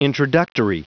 Prononciation du mot introductory en anglais (fichier audio)